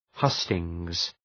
Προφορά
{‘hʌstıŋz}